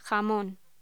Locución: Jamón